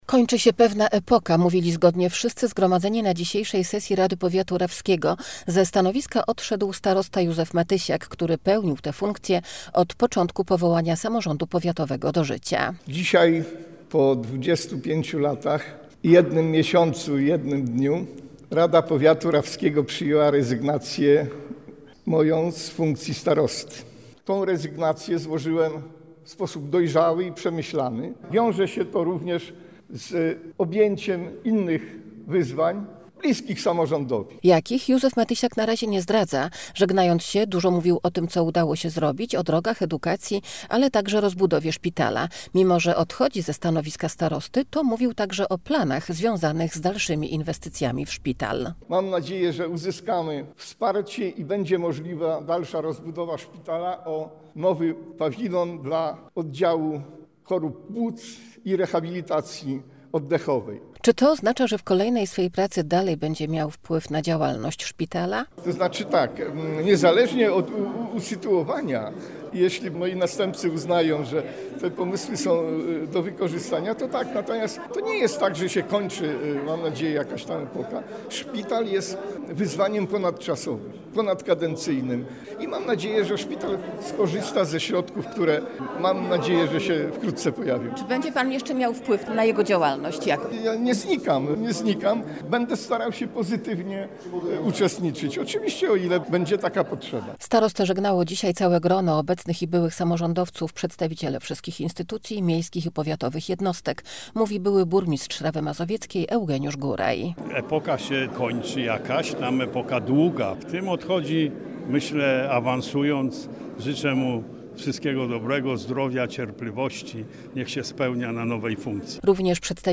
Kończy się pewna epoka – tak mówili zgodnie wszyscy zgromadzeni na piątkowej (2 lutego) sesji Rady Powiatu Rawskiego.